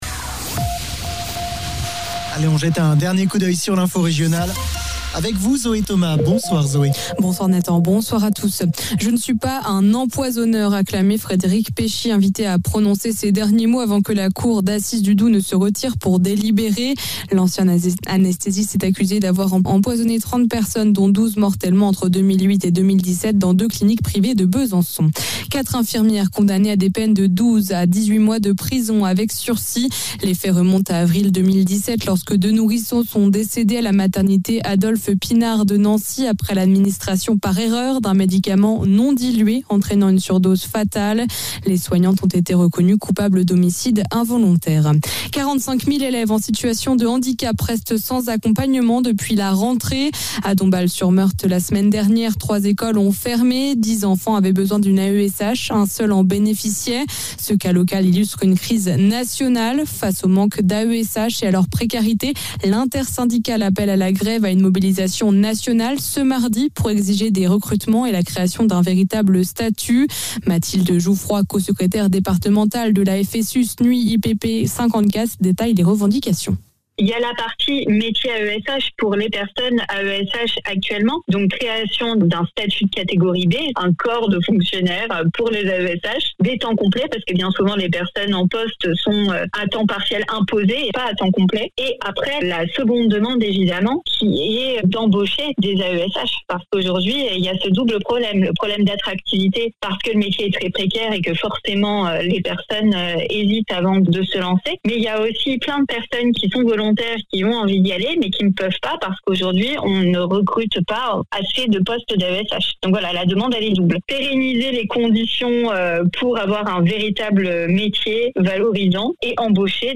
Le flash de 19h